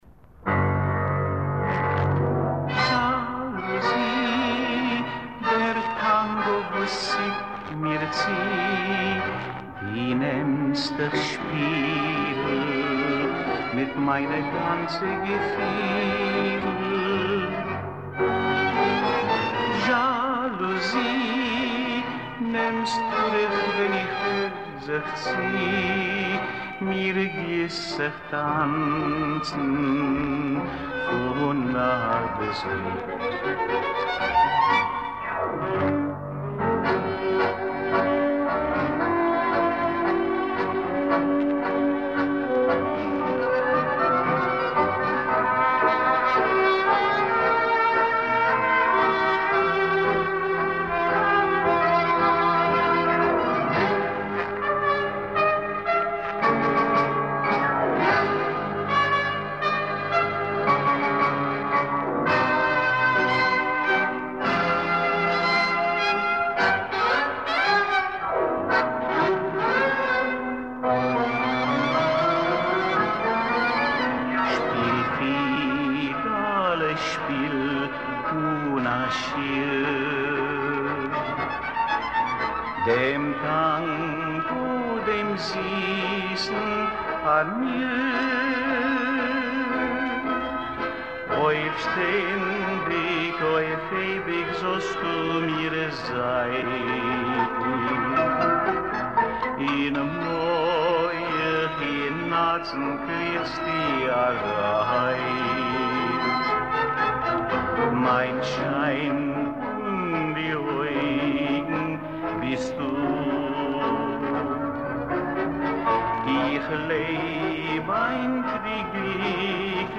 Следующий блок песен в нашей подборке - Танго.